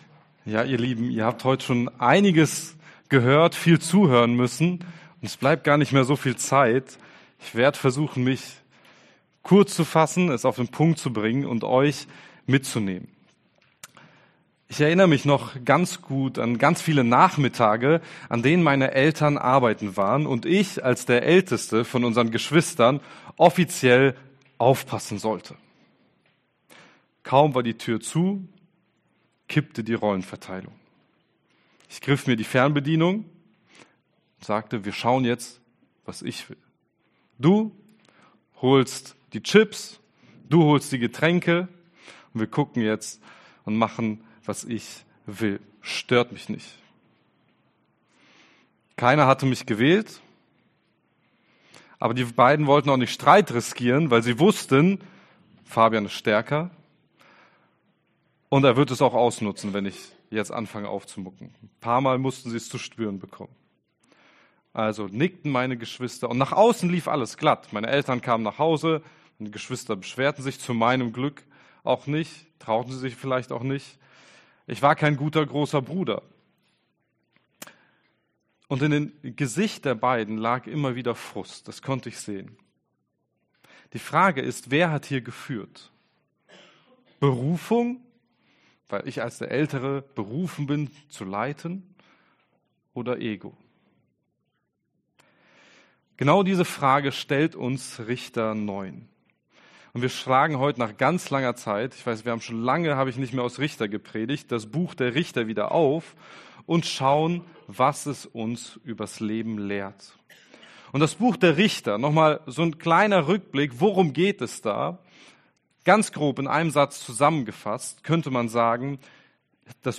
Juni 2025 Abimelechs Machtmissbrauch Prediger